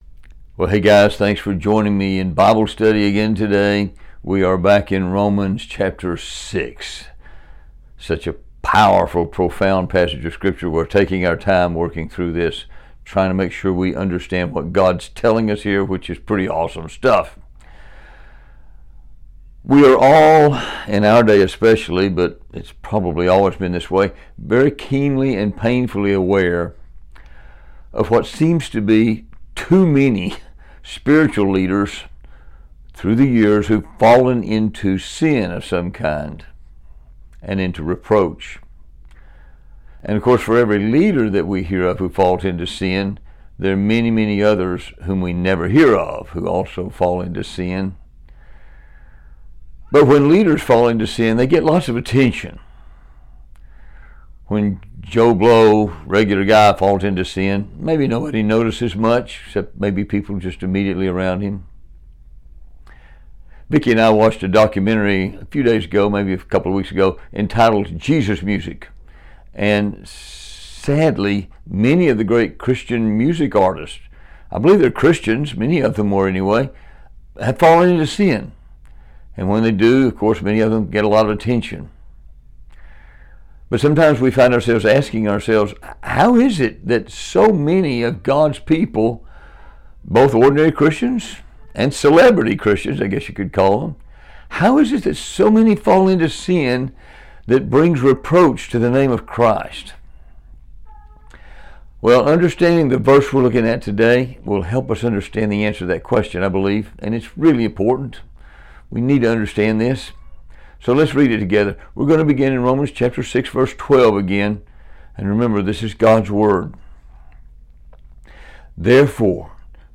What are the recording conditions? This is a Bible study that was shared in our Standing Firm class on September 1, 2024.